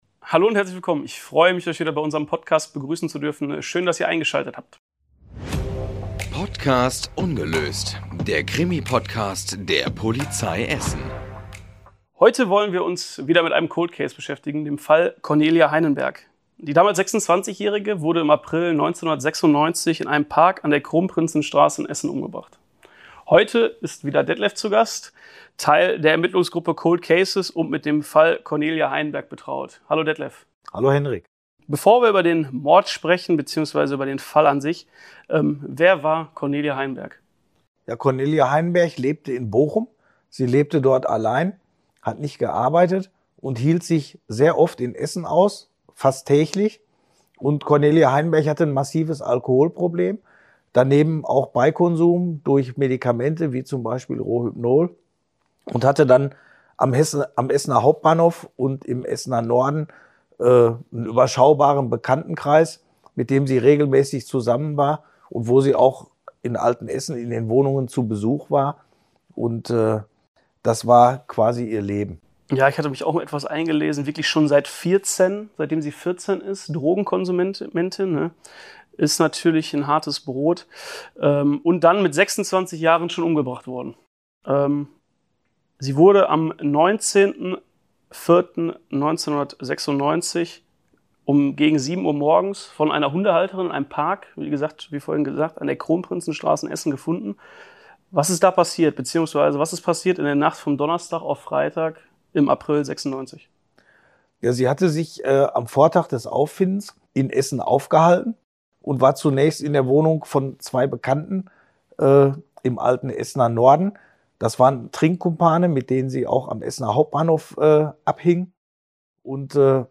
Die Kollegen der Kripo Essen kommen zu uns und erzählen von ihren spannendsten Kriminalfällen. Freut euch auf Einblicke in die Arbeit von Mordkommissionen und Ermittlungsgruppen sowie die neusten Informationen zu interessanten Kriminalfällen im Pott.